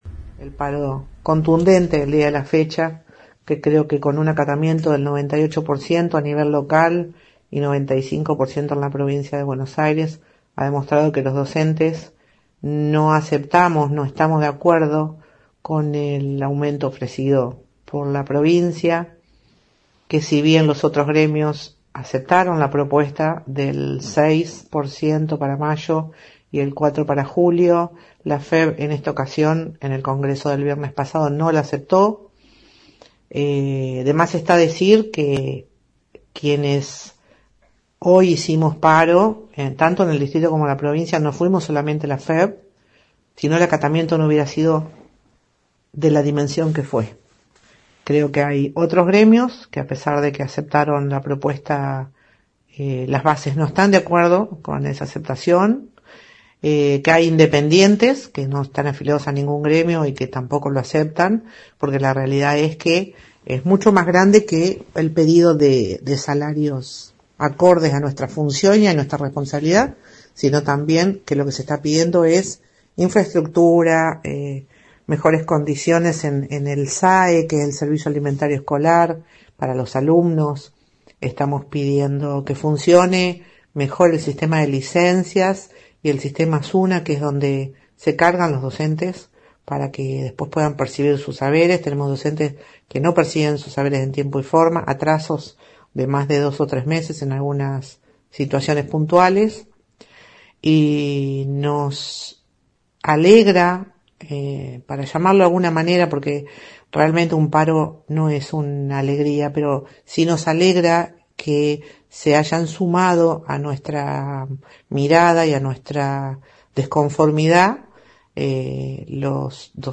En nuestra ciudad la 91.5 consultó a referentes de UELF y SUTEBA, quienes realizaron un balance del alto acatamiento del pasado martes (98%) que llevó adelante FEB quien no aceptó la oferta salarial de Kiciloff.